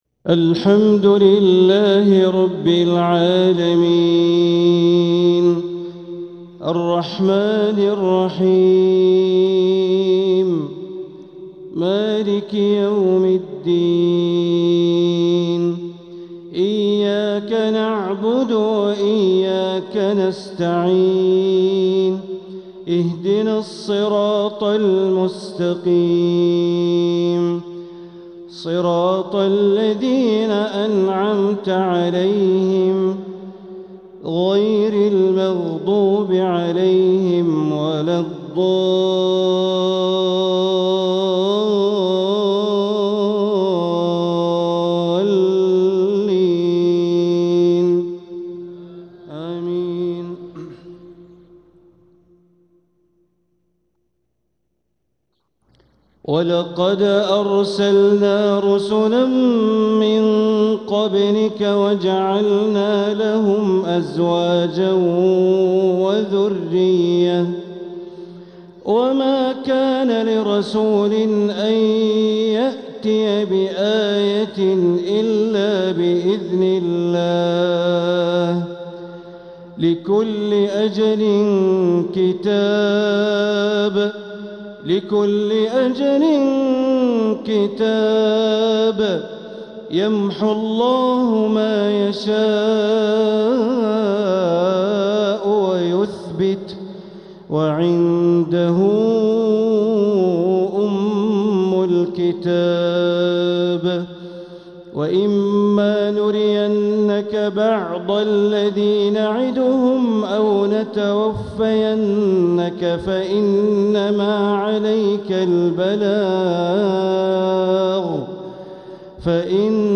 تلاوة من سورتي الرعد وفصلت | عشاء الثلاثاء ١ ربيع الثاني ١٤٤٧ > 1447هـ > الفروض - تلاوات بندر بليلة